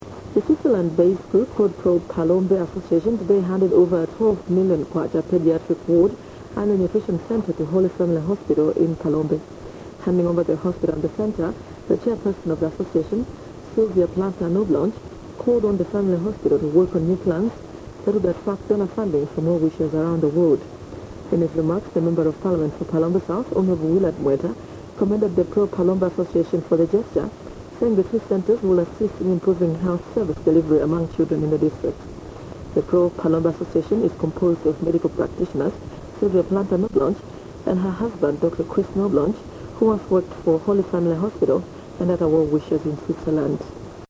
Malawi Radionews from the opening “Children′s Ward” (mp3/348 KB)
radio_rede.mp3